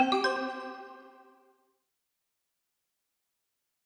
newNotif00.ogg